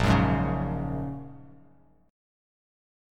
Bbm7#5 Chord
Listen to Bbm7#5 strummed